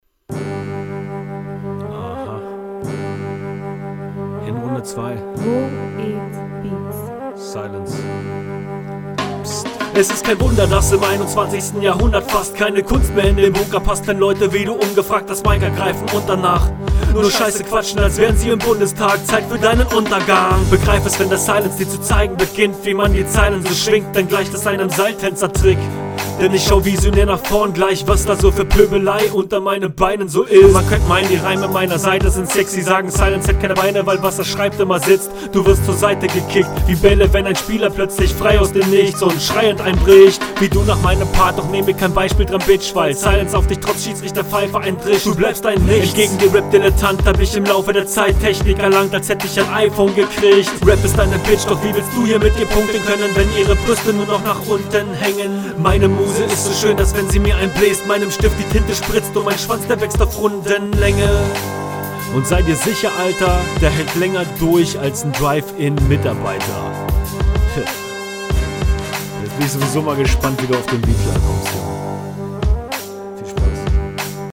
Coole Reinketten sind da das macht das Klangbild der Runde gleich viel besser!